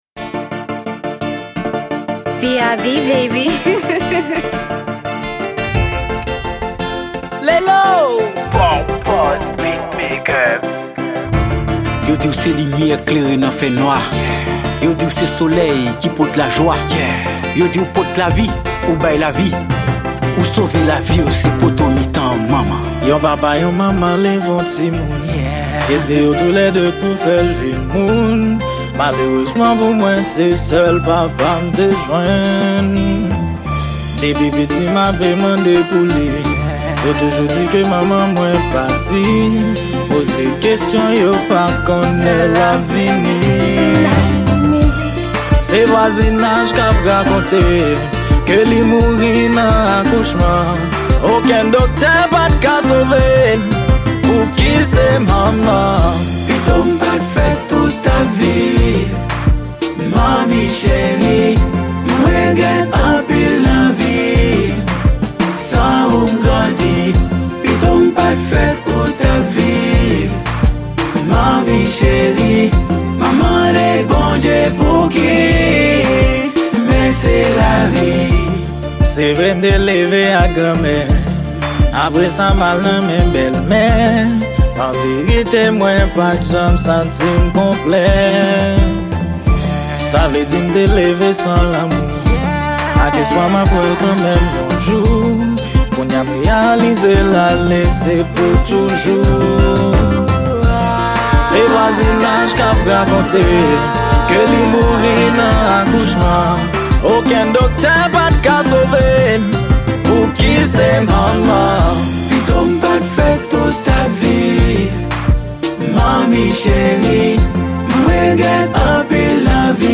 Genre: COMPAS.